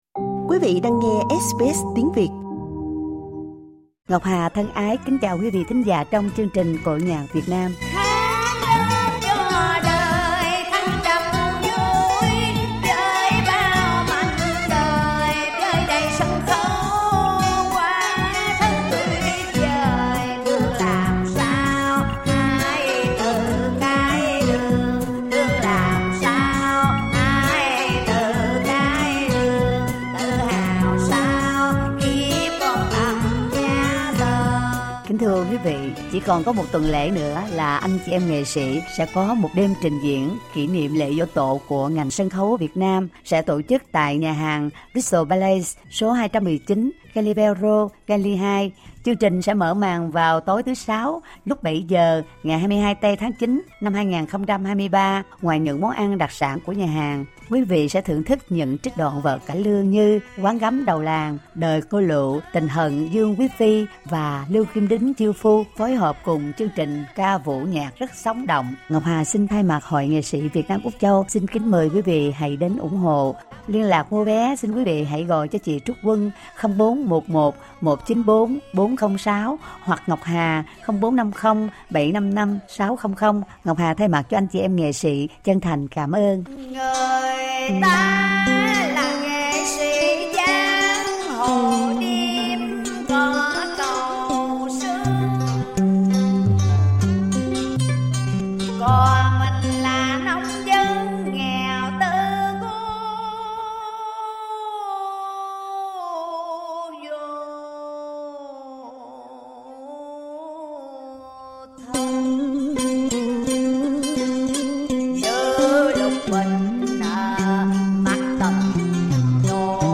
trích đoạn cải lương
1 lớp Nam Xuân